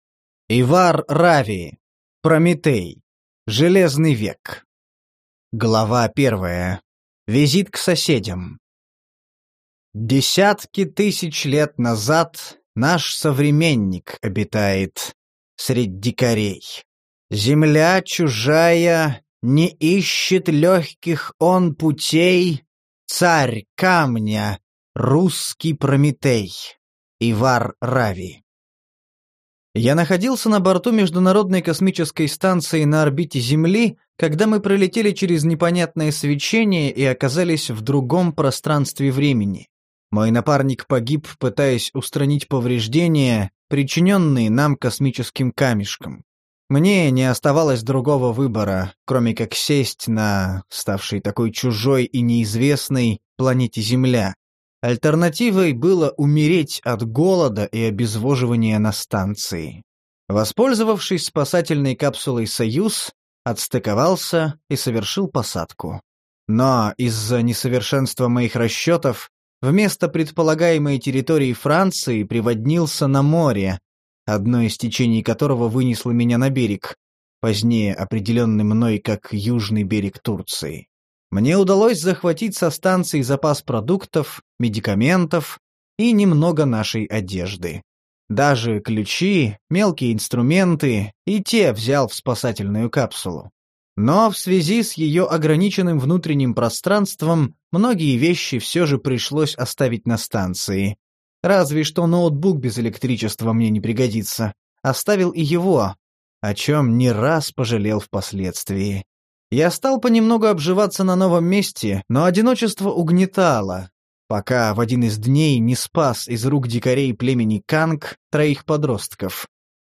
Аудиокнига Прометей: Железный век | Библиотека аудиокниг